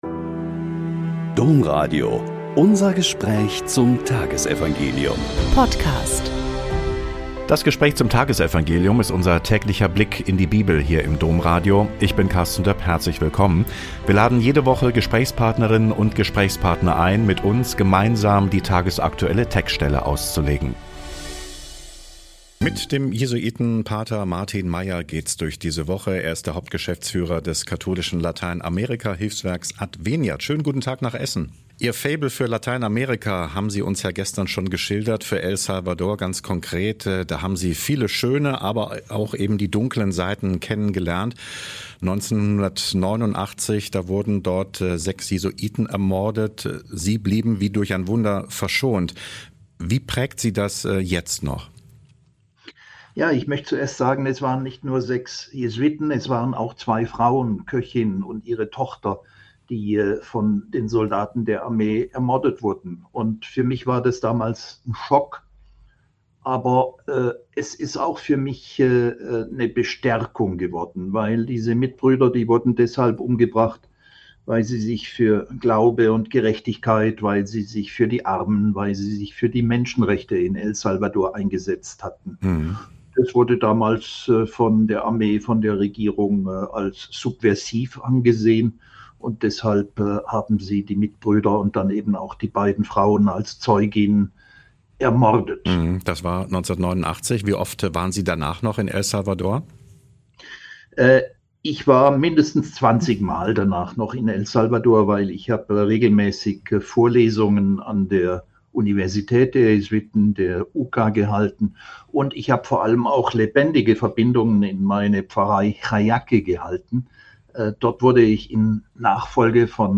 Mt 18,12-14 - Gespräch